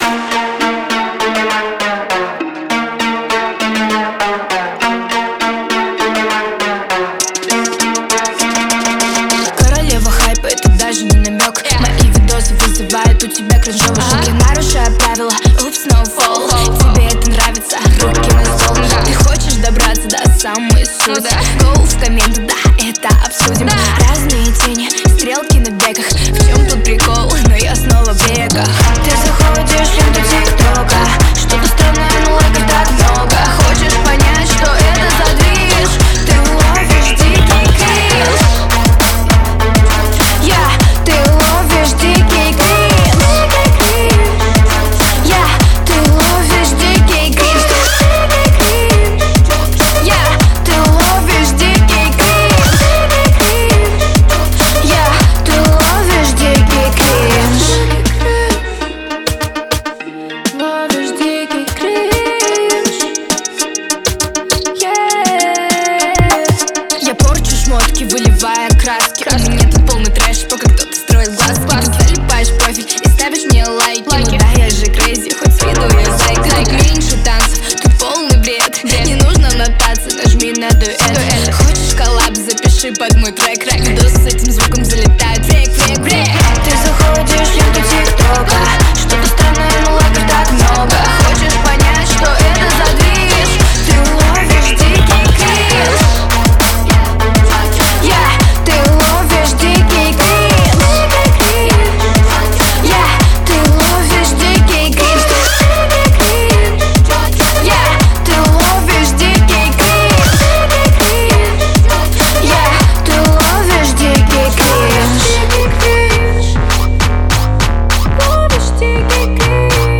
пронизан эмоциями